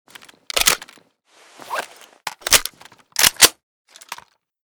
vityaz_reload_empty.ogg